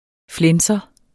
Udtale [ ˈflεnsʌ ]